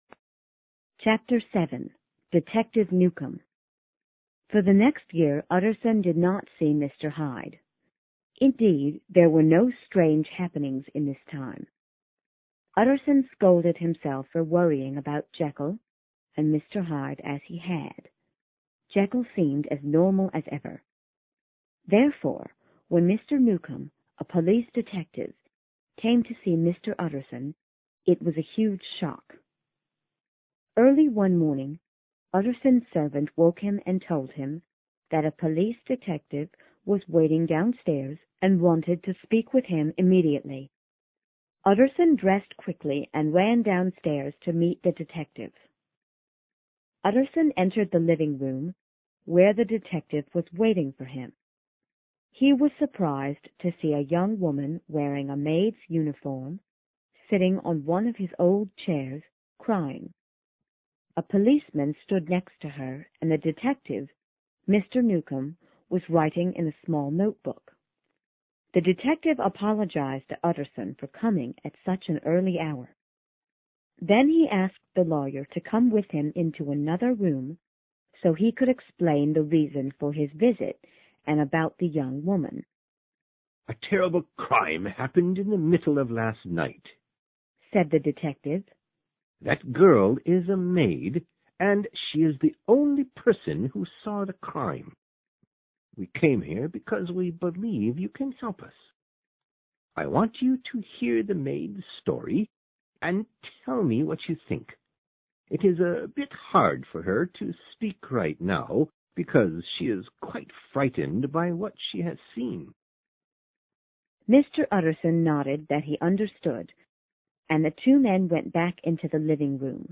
有声名著之化身博士07 听力文件下载—在线英语听力室